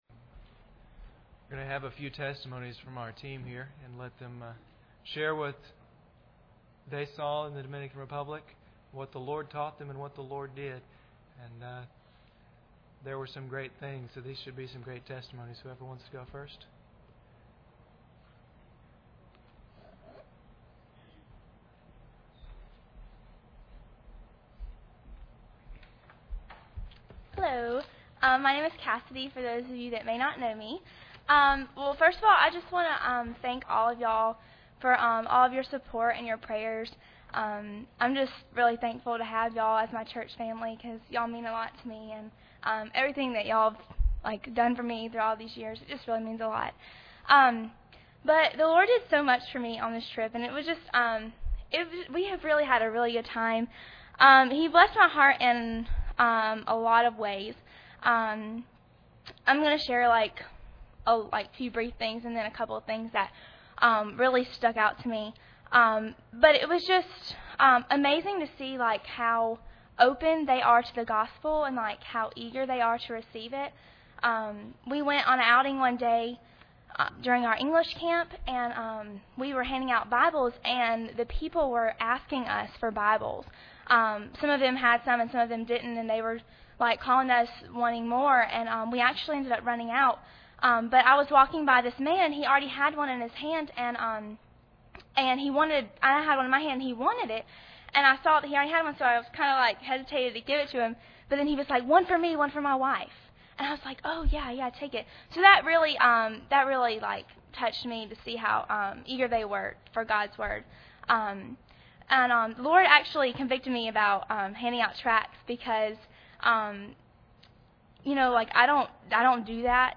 A group of young people from our church recently conducted an English camp in the Dominican Republic. This is their report.
Service Type: Sunday Evening